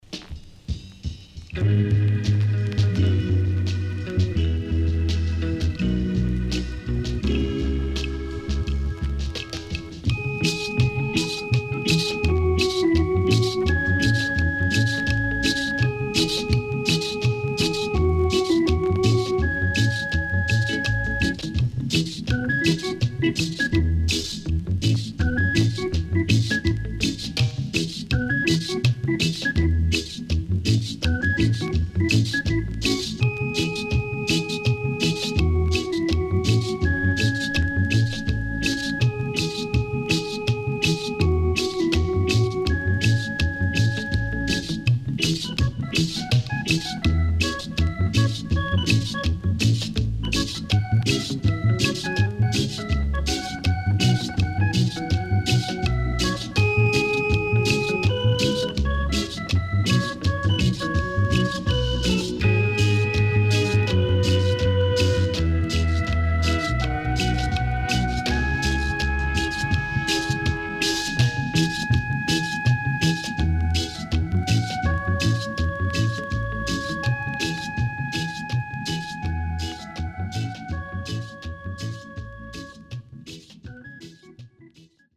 EARLY REGGAE  >  FUNKY REGGAE
SIDE A:少しチリノイズ入りますが良好です。